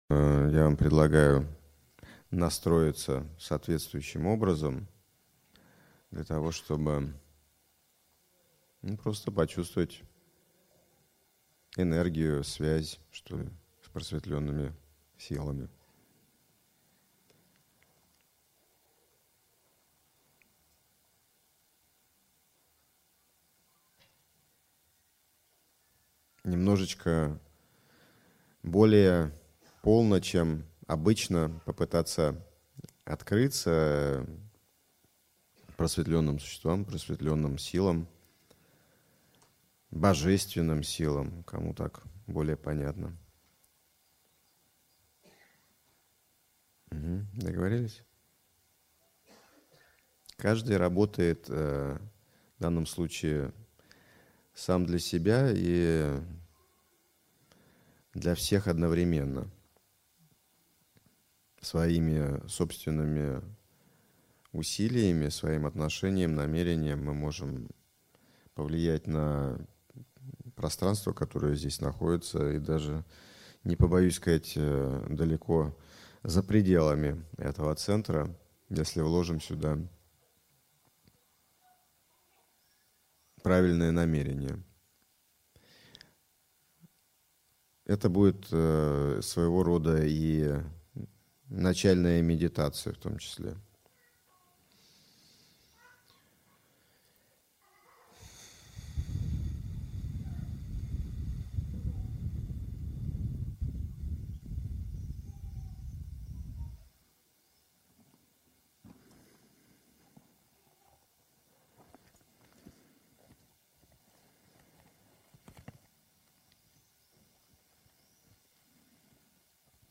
Аудиокнига Ключи к духовному и физическому исцелению | Библиотека аудиокниг